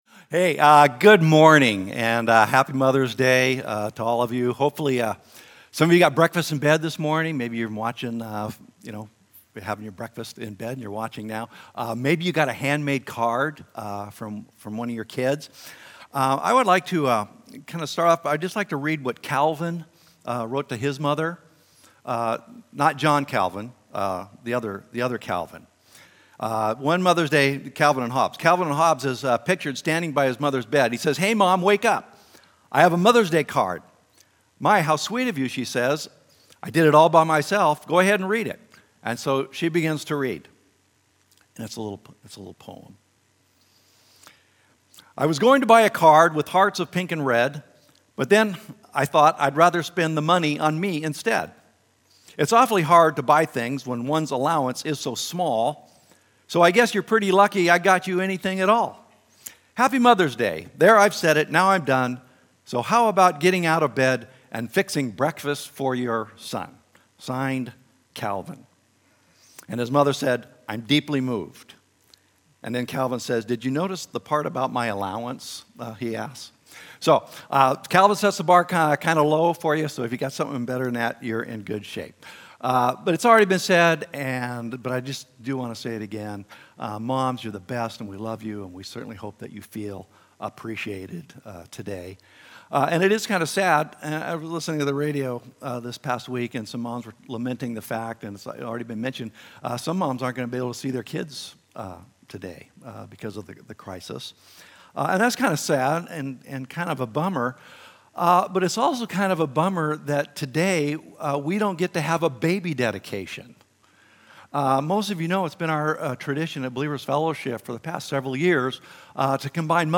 Tune in for our Mother’s Day sermon to learn about how the family came to be and what that means for us today.